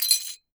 GLASS_Fragment_04_mono.wav